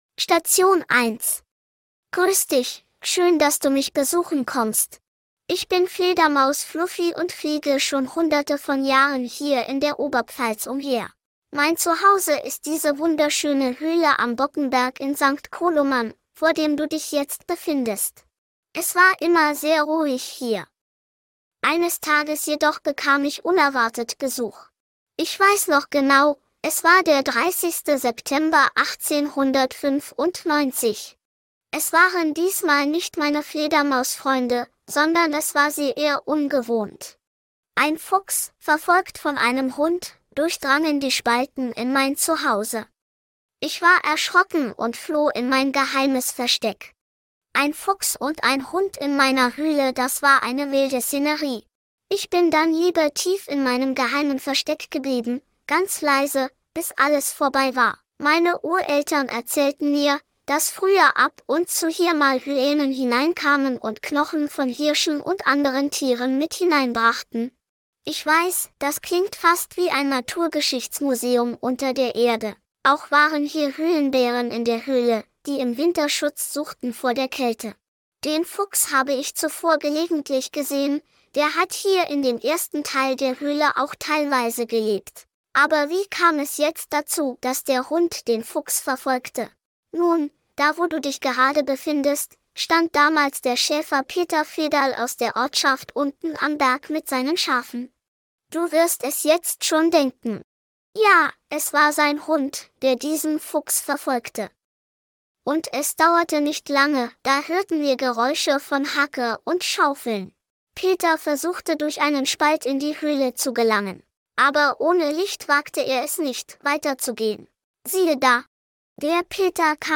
Audio Guide Deutsch